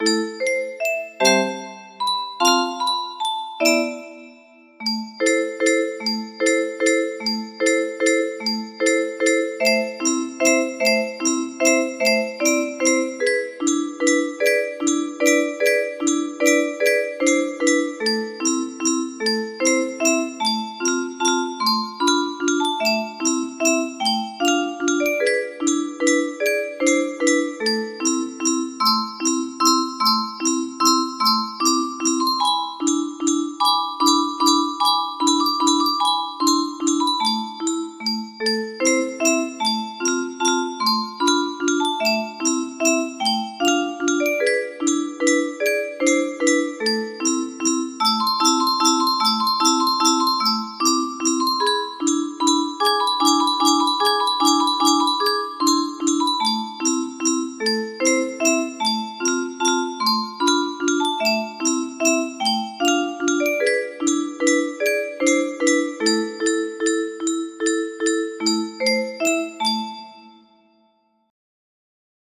Tumbalalaika - Jewish melody music box melody